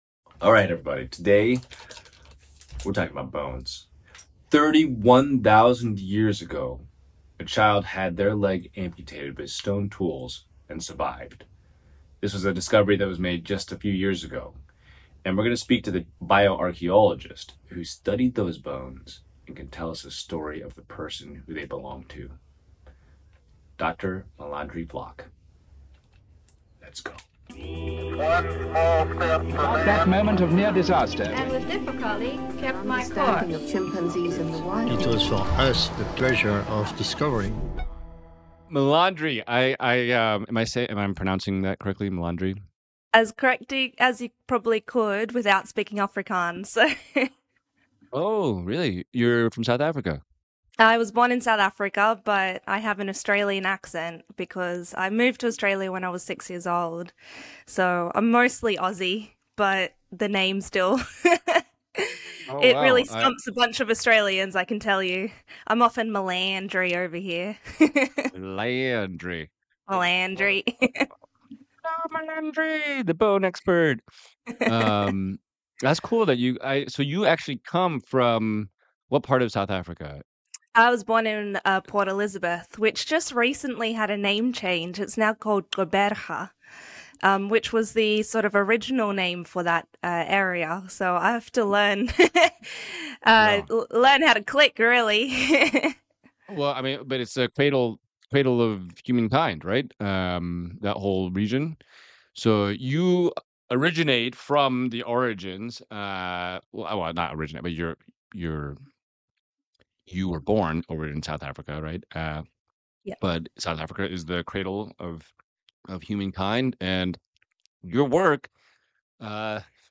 Embark on a thrilling journey with host Albert Lin and his guest as they venture back 30,000 years into a remote Bornean cave on the latest episode of the Explorer Albert podcast.